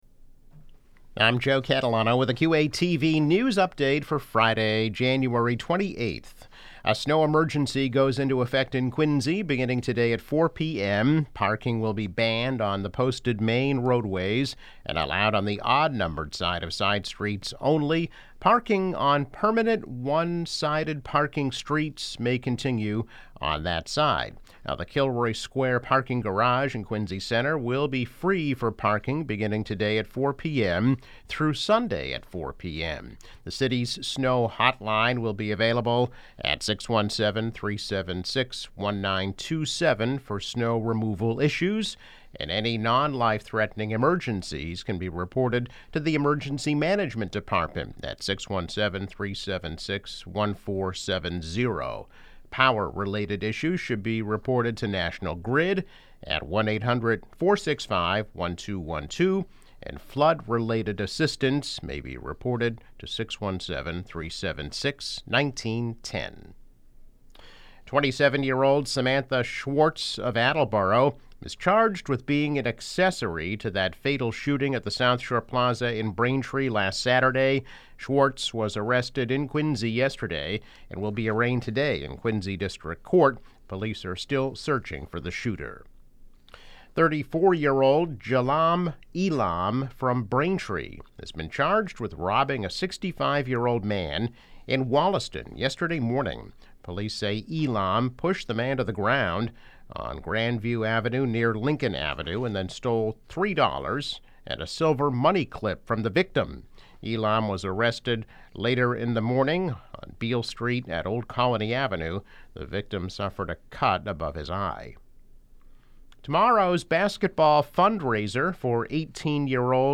News Update - January 28, 2022